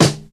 • Short Snare Drum D Key 73.wav
Royality free snare one shot tuned to the D note. Loudest frequency: 1791Hz
short-snare-drum-d-key-73-Nb6.wav